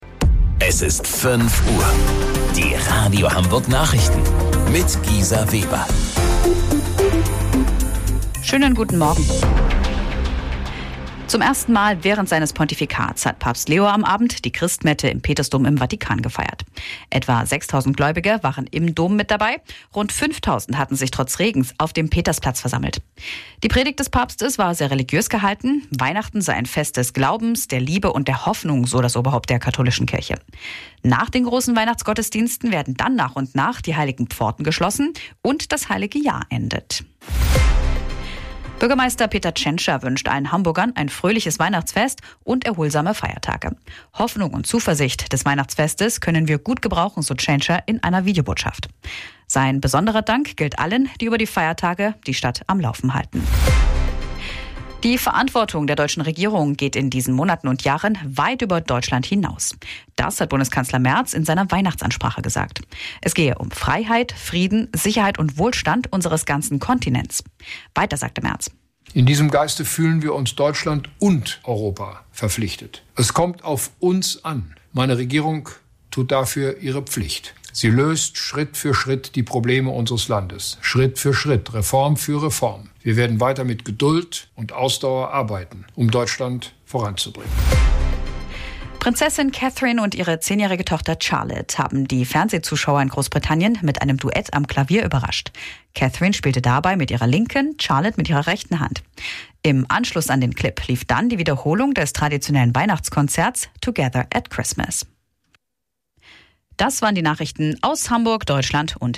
Radio Hamburg Nachrichten vom 25.12.2025 um 05 Uhr